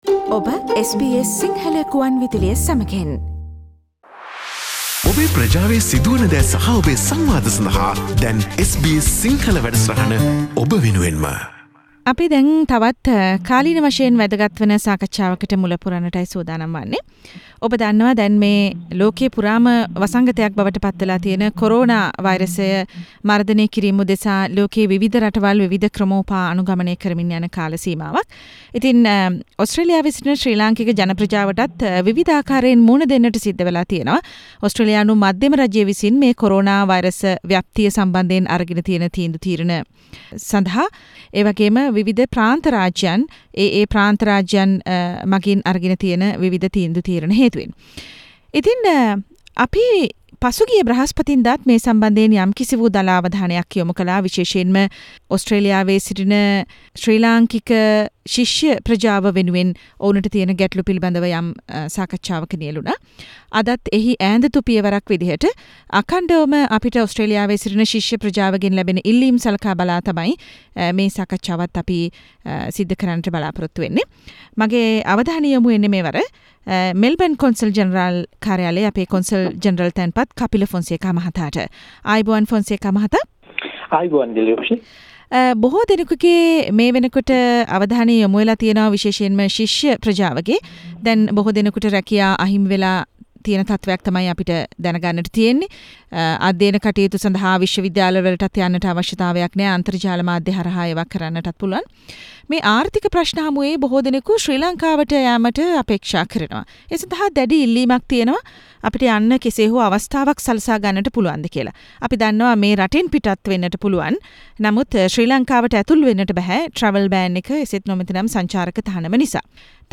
Mr: Kapila Fonseka, Consul General of Sri Lanka in Melbourne, Victoria, Speaking to SBS Sinhala Radio